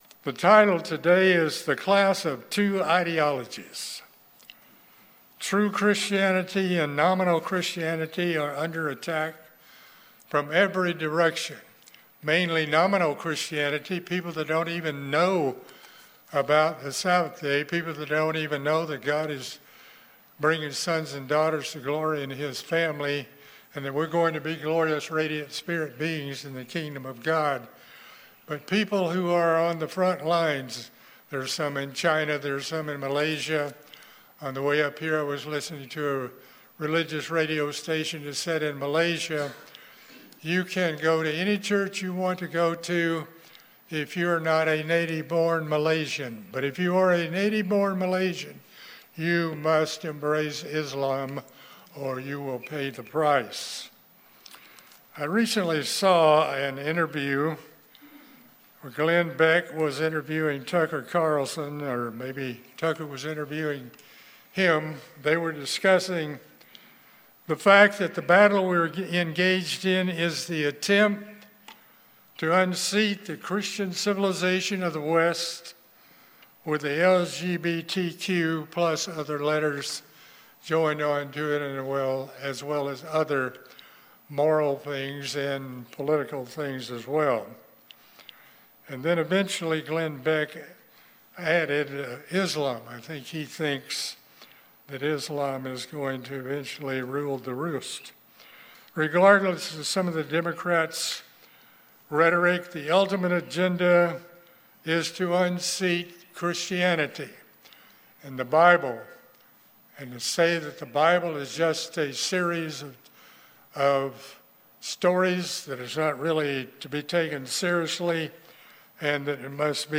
In this sermon we summarize the clash between the two systems that are competing in America today. The progressives are trying to replace nominal Christian culture and its foundation---the word of God. We show from Scripture what God says about many of the moral issues of the day and that God expects us to serve as watchmen and always be prepared so no day comes on us unexpectedly.